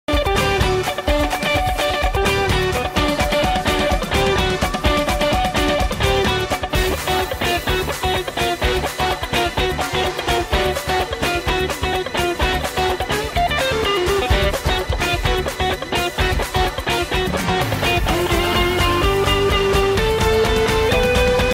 Gospel Guitar? Funk?